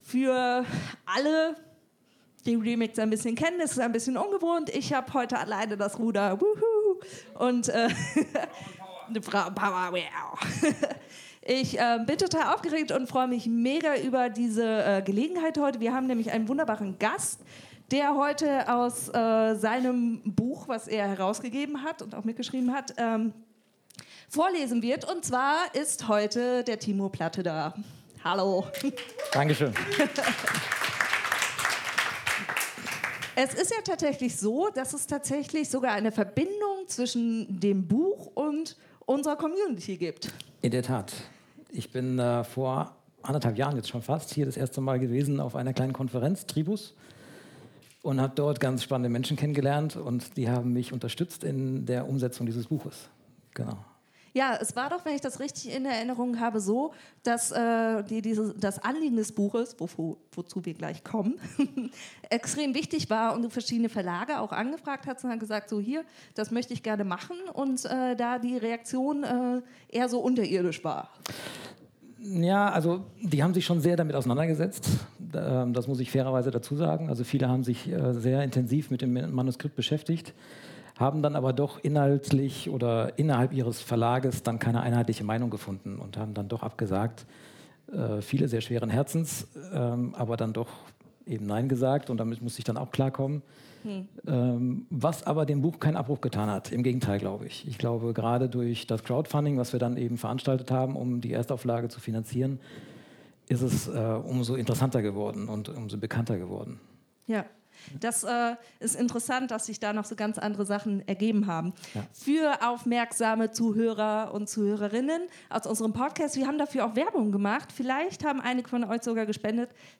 Episode 78 – Der lange Weg queerer ChristInnen zu einem authentischen Leben. Interview